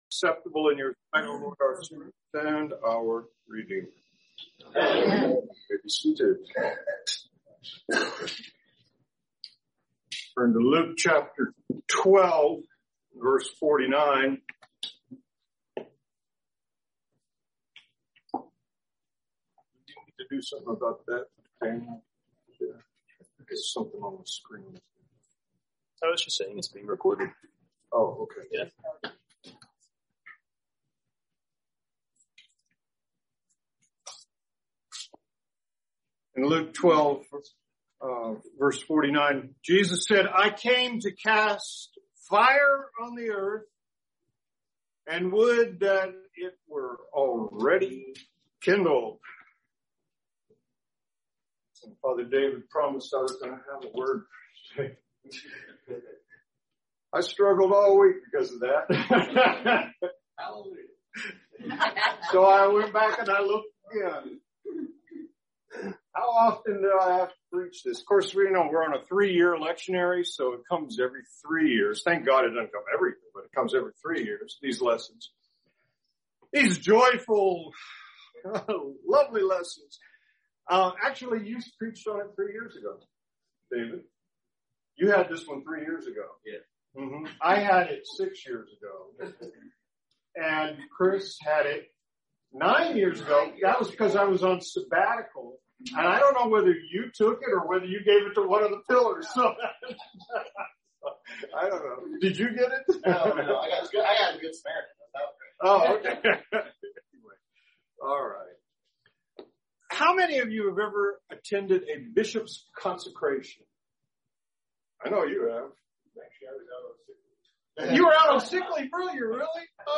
Sermons - Church of the Resurrection CEC